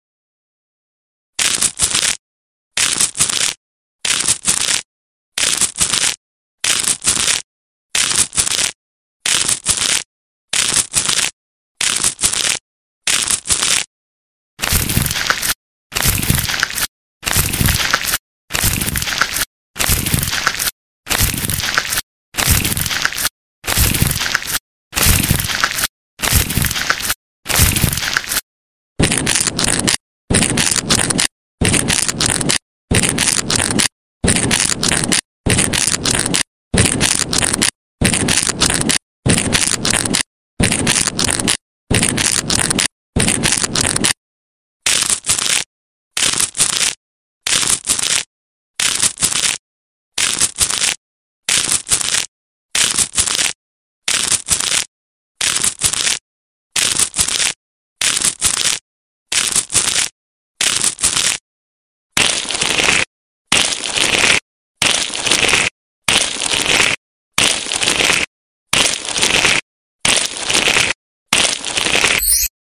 ASMR tired feet spa 👣| sound effects free download
ASMR tired feet spa 👣| gentle foot massage-sleep inducing - sounds